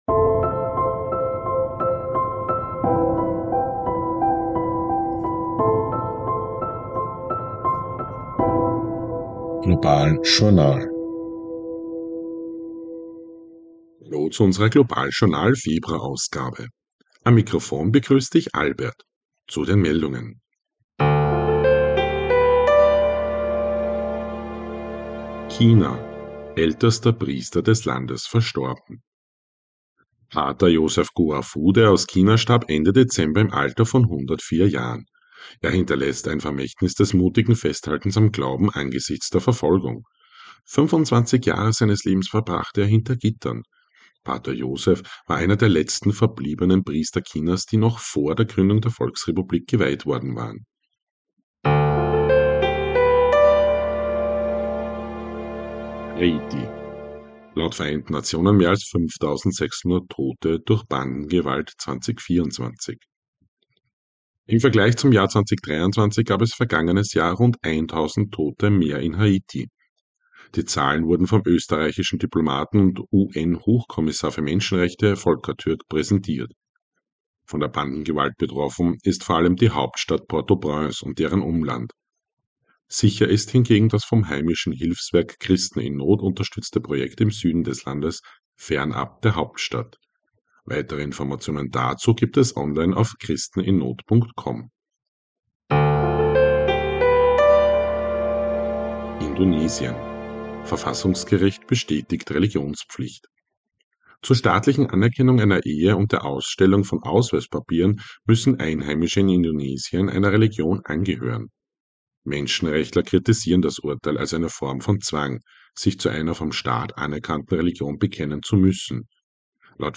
News Update Februar 2025